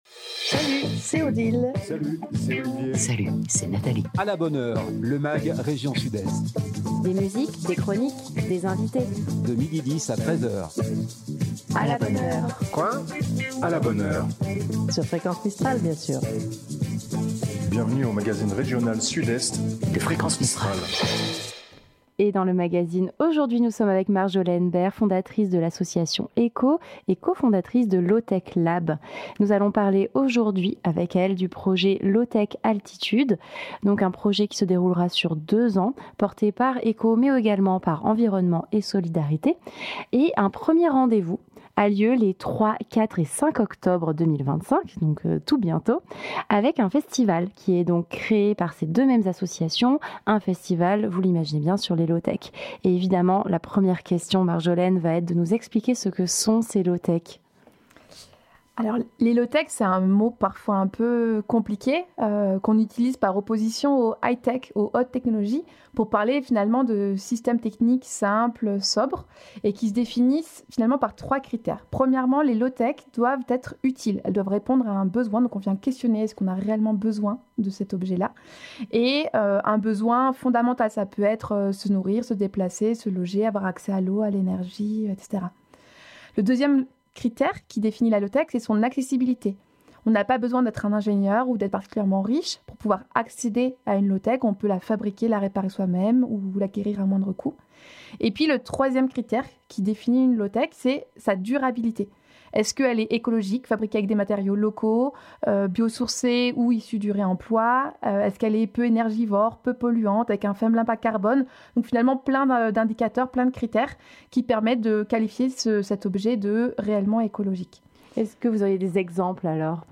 Chaque jour, une antenne différente présente le magazine.